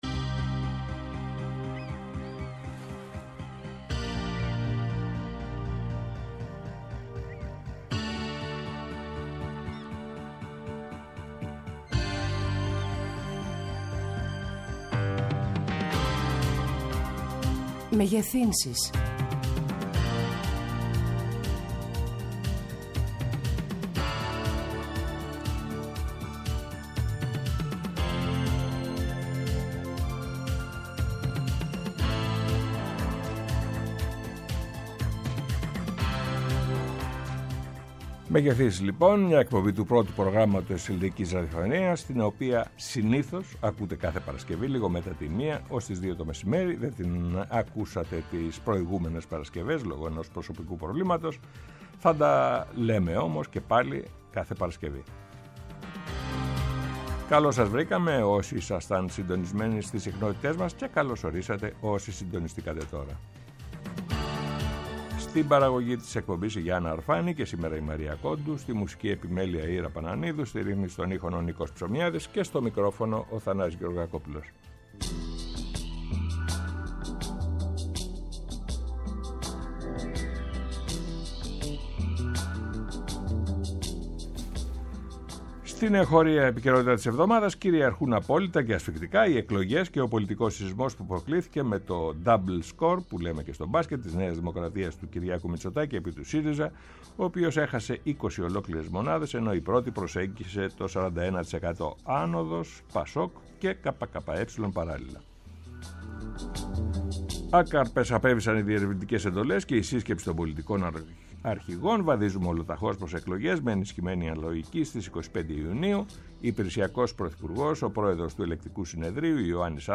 Καλεσμένοι τηλεφωνικά
Μια εκπομπή στο Πρώτο Πρόγραμμα της Ελληνικής Ραδιοφωνίας